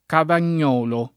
cavagnolo [ kavan’n’ 0 lo ]